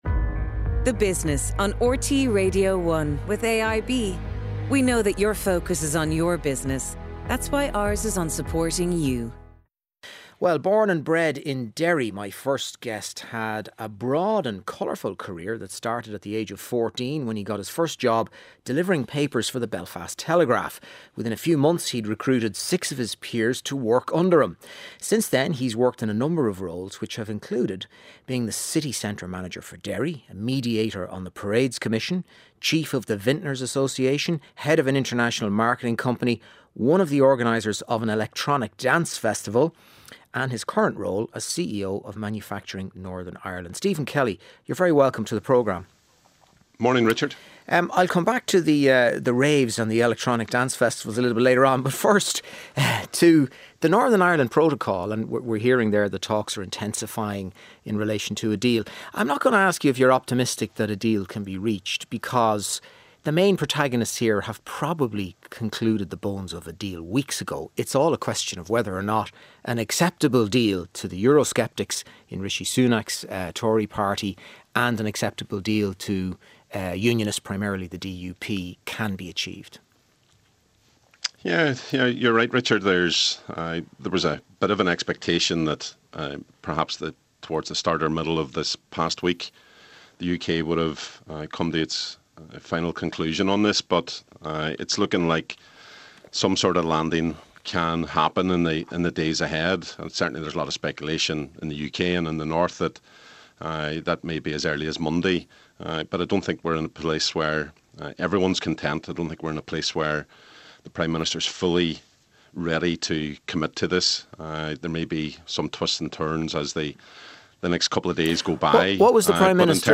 7:35am Sports News - 24.07.2023